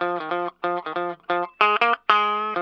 LO-FI 1.wav